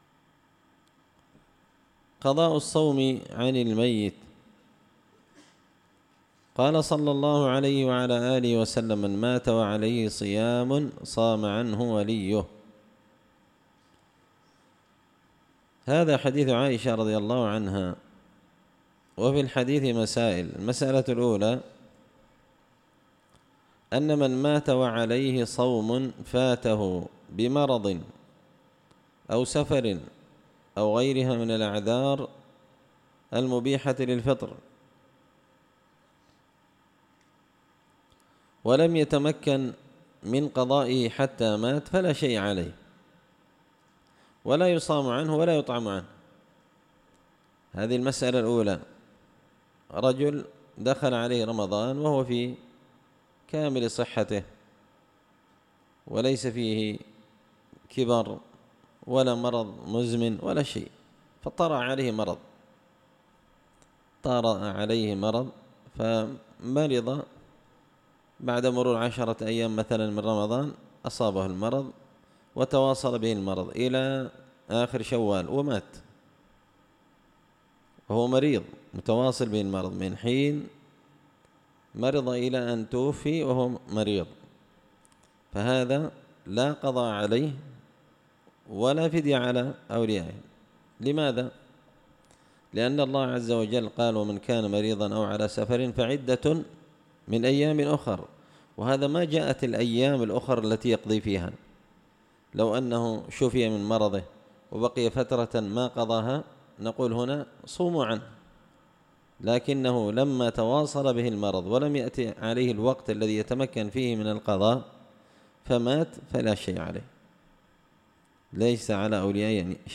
منتقى الأفنان في فقه الصوم وأعمال رمضان الدرس الحادي عشر
دار الحديث بمسجد الفرقان ـ قشن ـ المهرة ـ اليمن